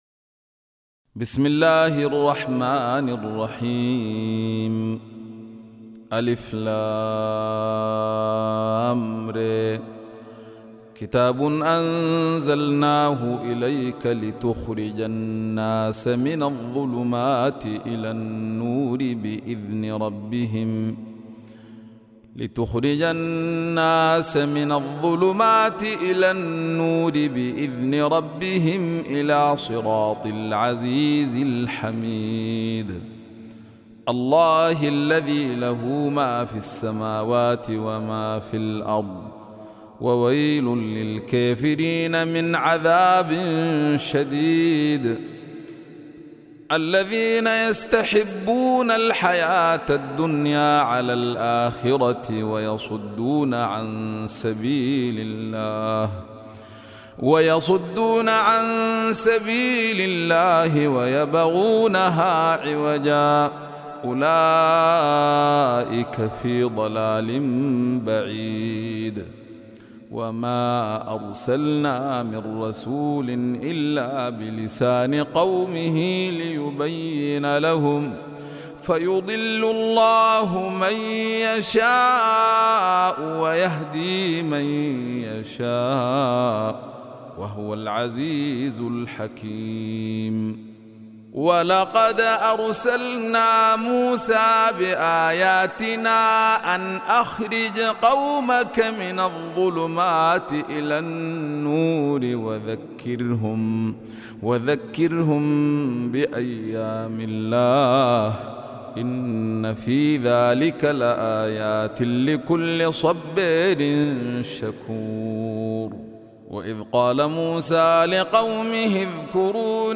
Surah Ibrahim mp3 Recitation